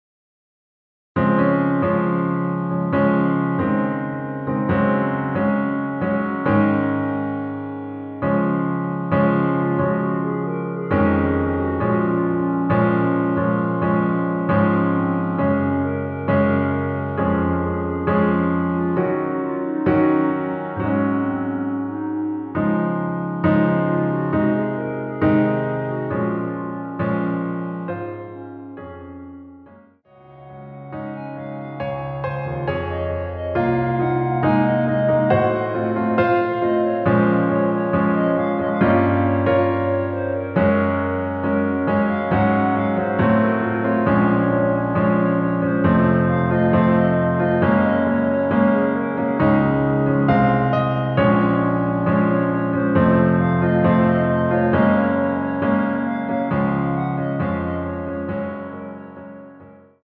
반주를 피아노 하나로 편곡하여 제작하였습니다.
원키(Piano Ver.) (1절+후렴)멜로디 포함된 MR입니다.
Db